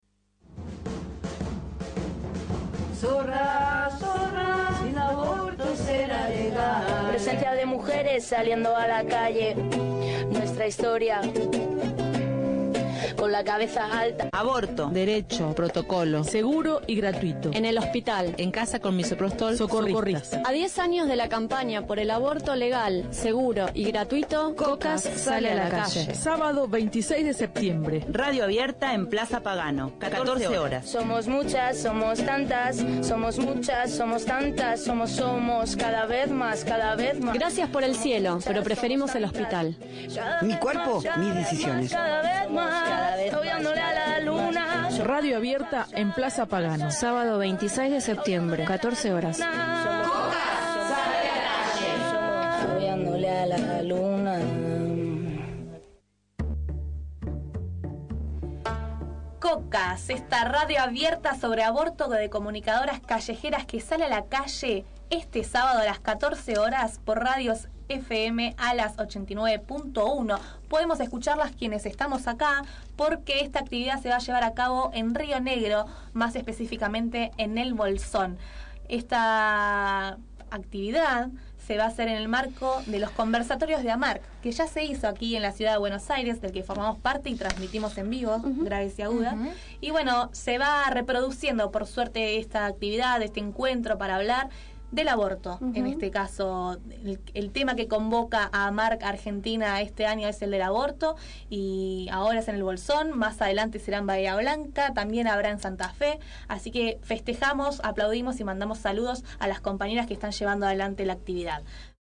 Difundimos la actividad de las Comunicadoras Callejeras (Co-Cas). Radio abierta sobre Aborto.